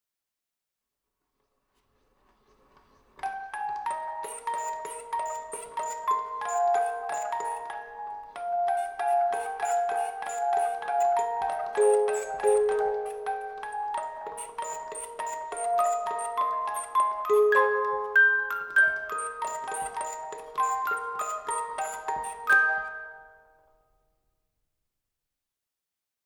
von Spieluhr | MERZ Beruhigungsmusik